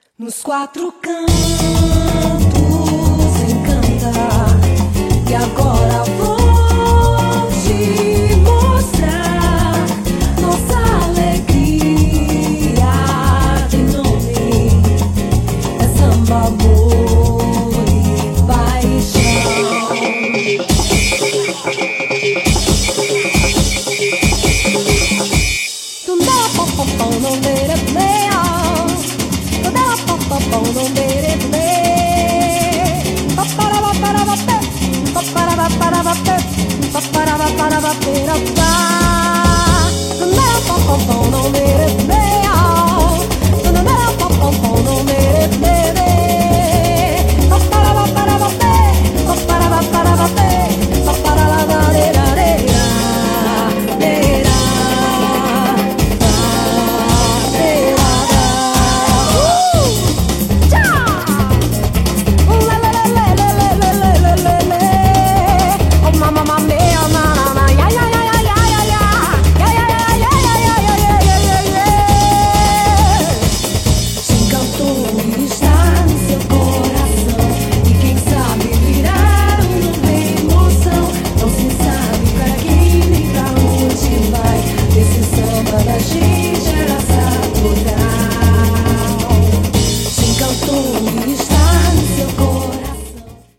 BPM94-272
Audio QualityMusic Cut